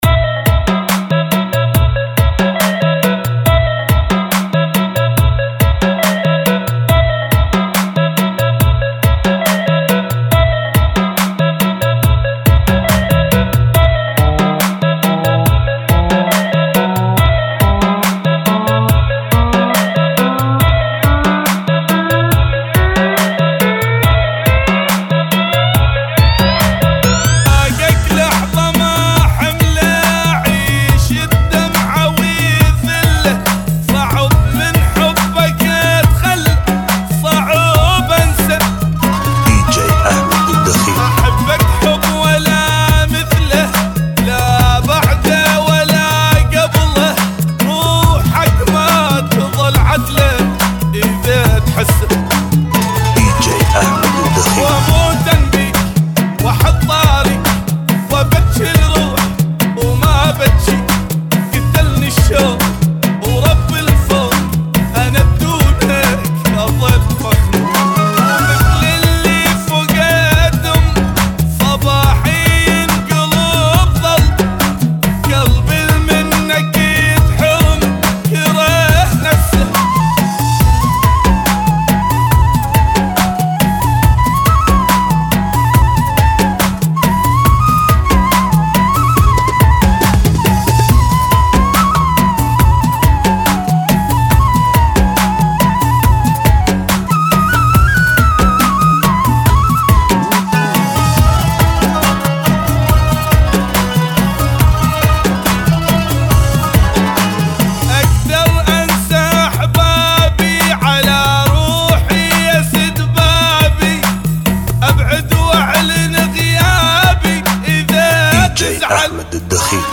Funky Remix